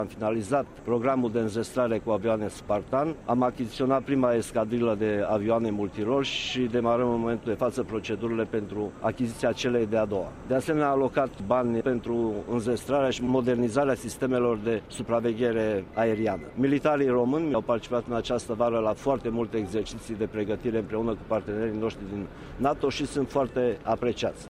Așa a declarat ministrul apărării, Mircea Duşa, la ceremonia dedicată Zilei Aviaţiei Române, care s-a desfăşurat la Monumentul Eroilor Aerului din Piaţa Aviatorilor din capitală.